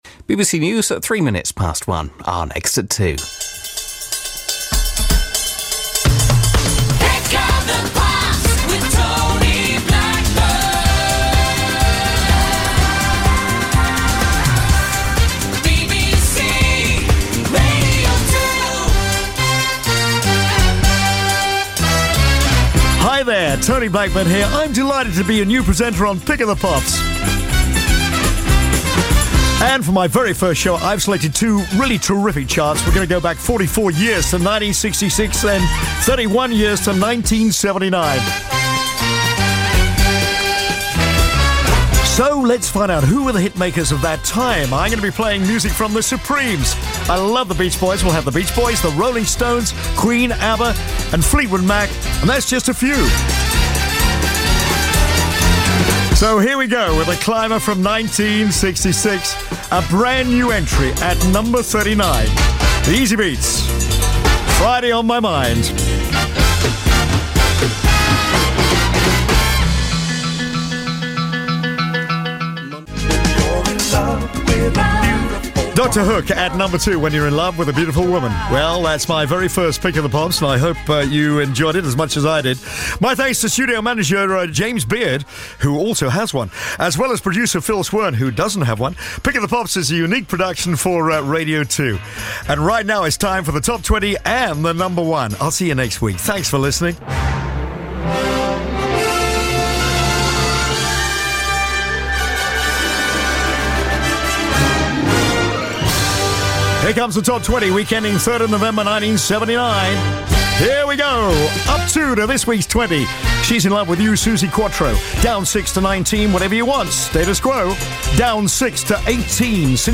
Here, he hosts his first edition of the legendary Pick of the Pops programme on a reinvigorated BBC Radio 2; a programme he was born to do.